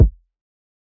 Metro Kicks [Hard Kick].wav